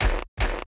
foot_1.wav